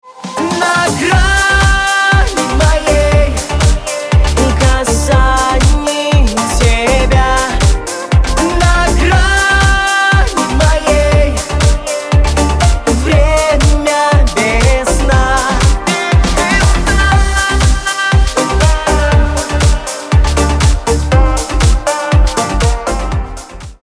• Качество: 128, Stereo
поп
мужской вокал
dance